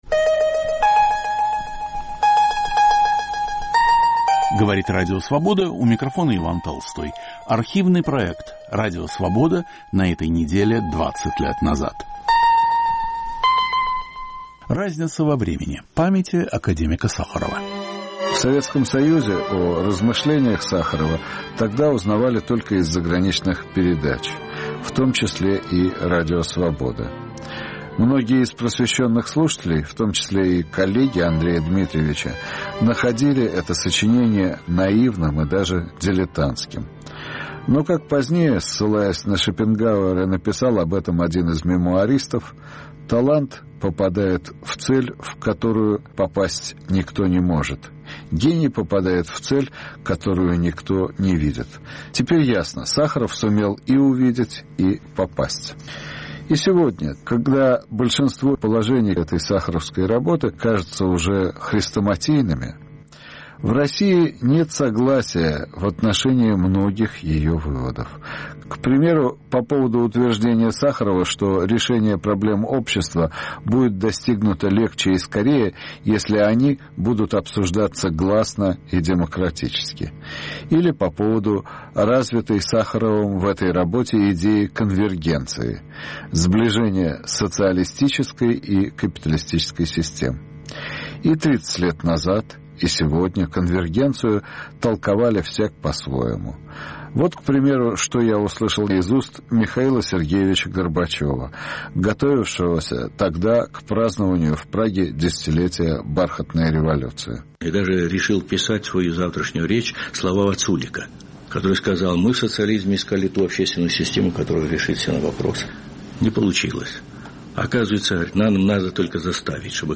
Передача по материалам звукового архива Радио Свобода.